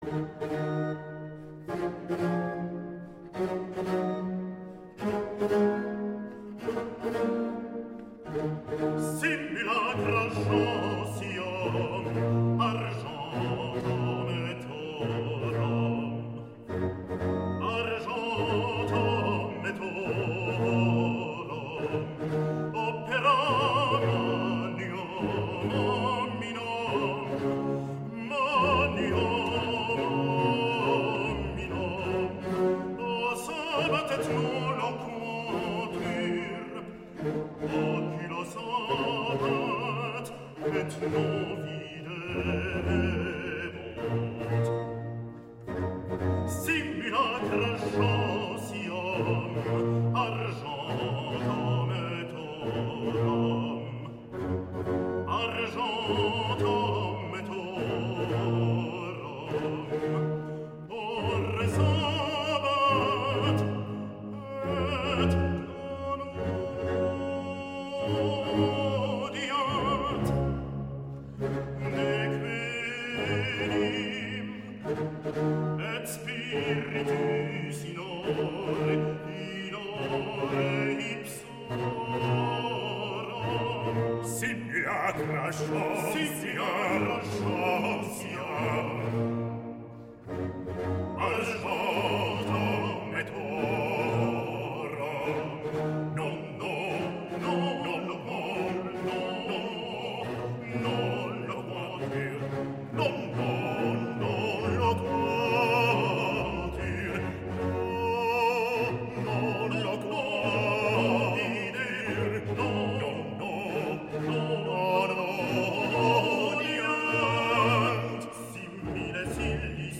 Grand motet
Prelude - Recit de Baryton - Duo de Baryton et Basse